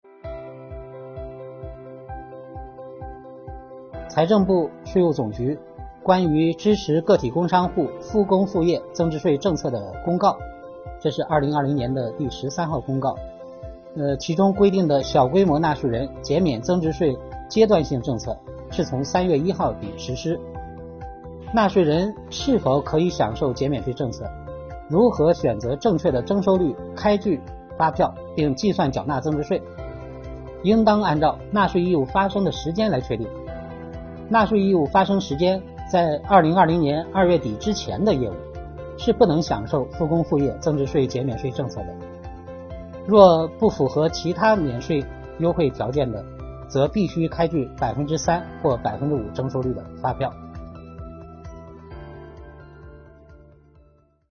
继“税费优惠政策解读”“你来问我来答”系列视频讲座后，税务总局“税务讲堂”近日又推出“热点政策解读”系列课程，为纳税人、缴费人解读最新出台的税费政策，助力企业复工复产。